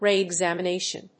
/rìːɪgz`æmənéɪʃən(米国英語), ri:ɪˌgzæmʌˈneɪʃʌn(英国英語)/